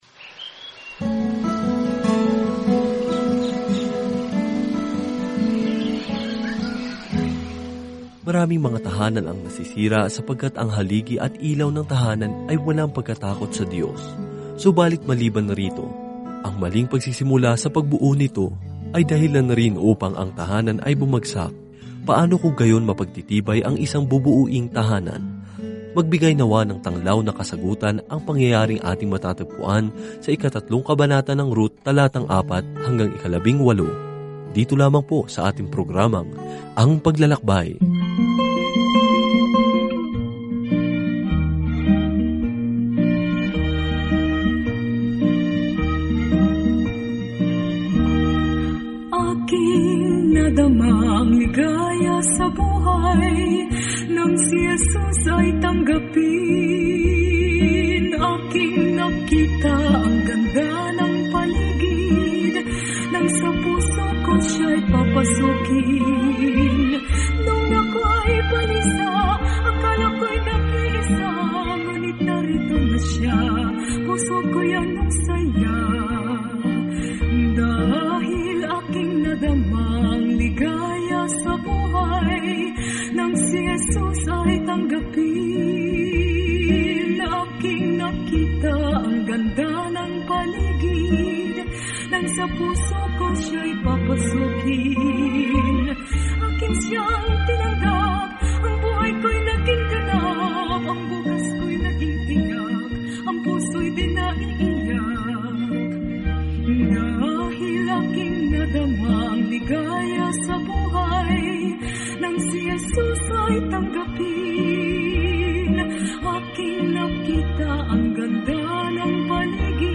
Araw-araw na paglalakbay kay Ruth habang nakikinig ka sa audio study at nagbabasa ng mga piling talata mula sa salita ng Diyos.